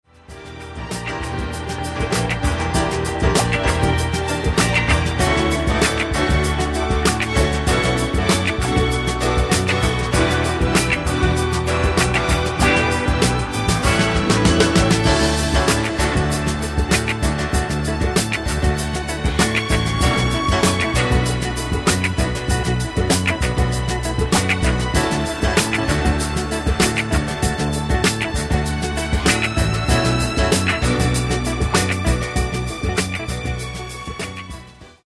Genere:   Soul | Disco